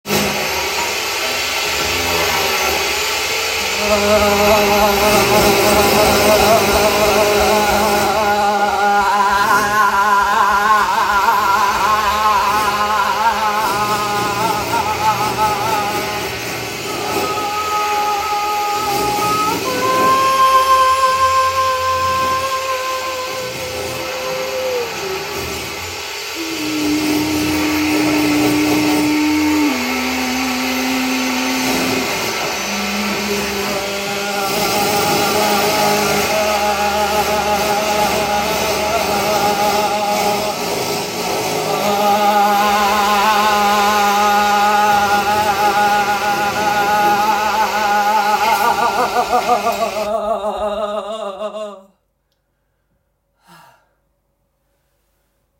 en The Tier 8 individual contribution explores body-voice interaction with the sound of a jackhammer, present during Week 8 of the residency at Alta studio, under renovation.
en jackhammer
en foundsound
en externalnoise